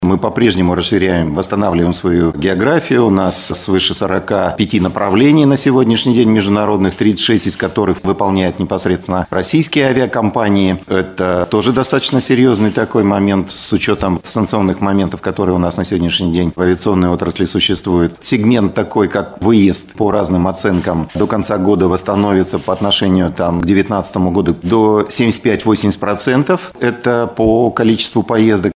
на пресс-конференции в агентстве «ТАСС-Урал».